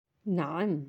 (na”am)
How to say yes in Arabic
naam.aac